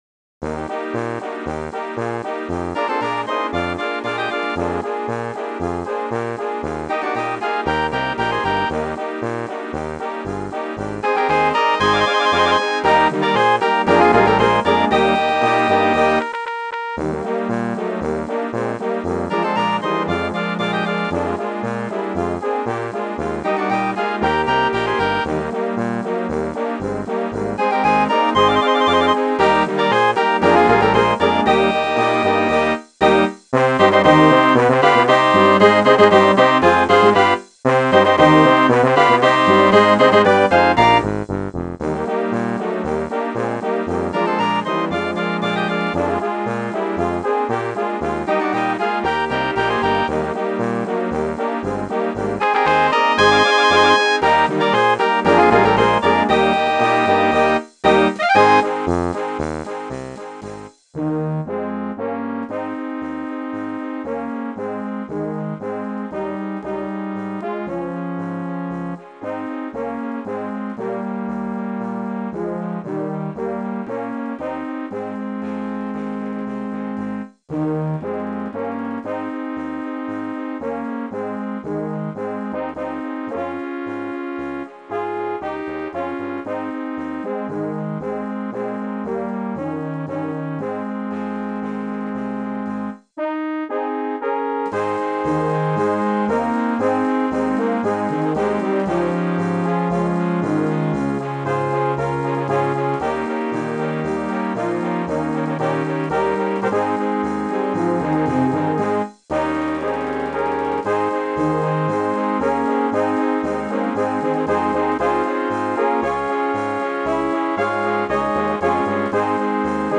schwungvolle Polka für Blasorchester